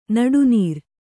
♪ naḍu nīr